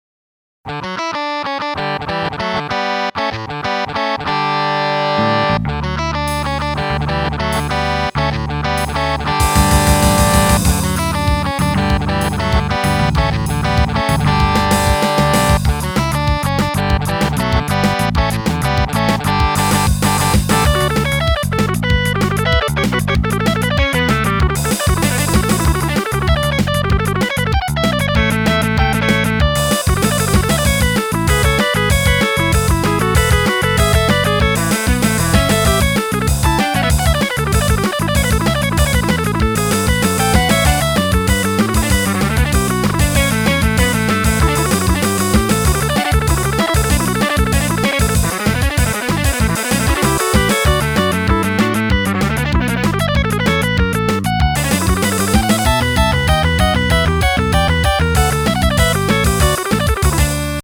(2007년 3월 30일) 기타 속주